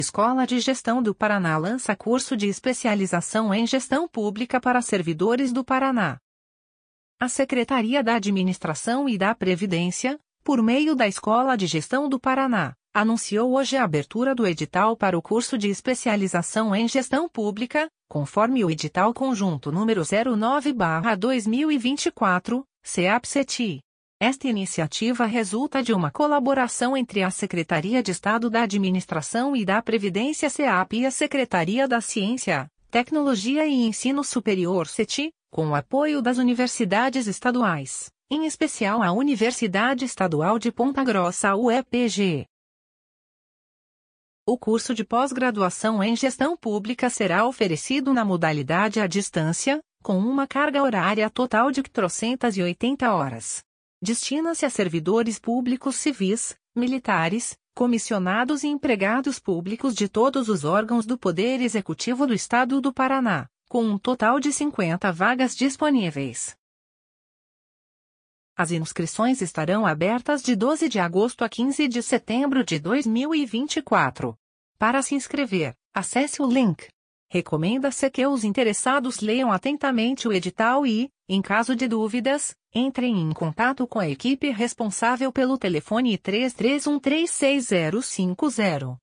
audionoticia_especializacao_gestoa_publica.mp3